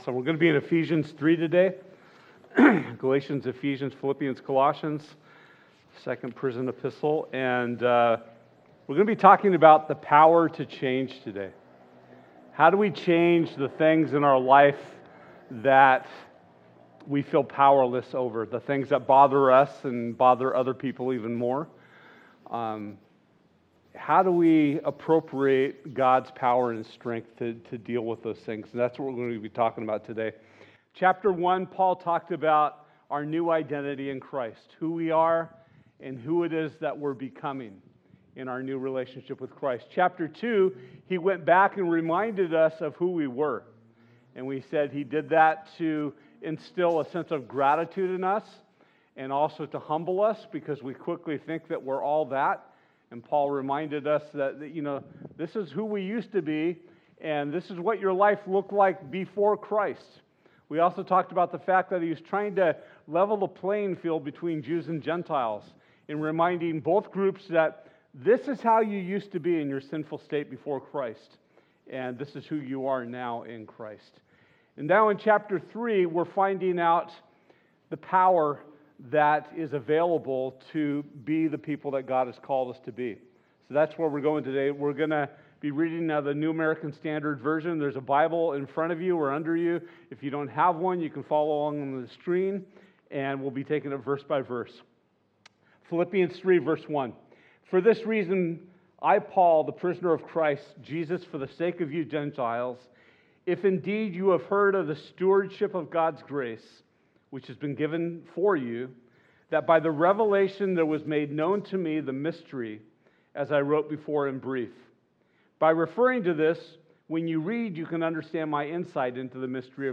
Ephesians 3 Service Type: Night of Worship This week we’ll be looking at Ephesians 3:1-21.